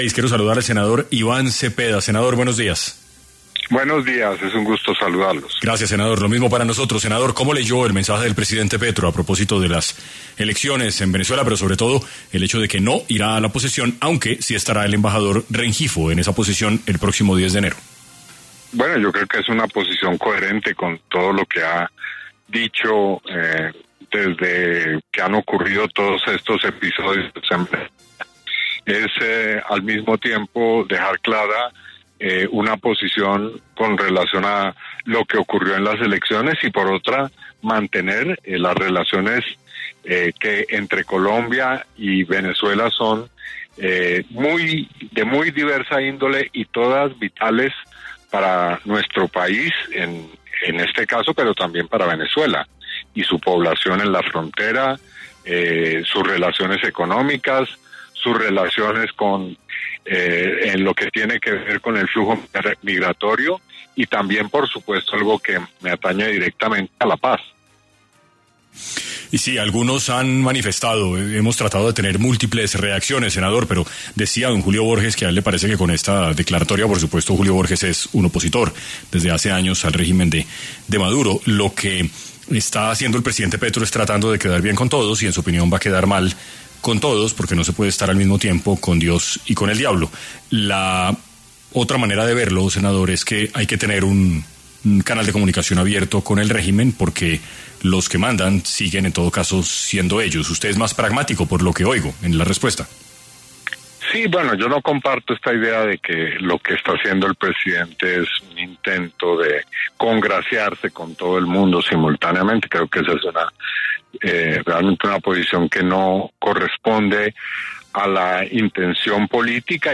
En Caracol Radio estuvo el senador Iván Cepeda, quien habló sobre la decisión del presidente Gustavo Petro de no asistir a la poseción de Nicolás Maduro en Venezuela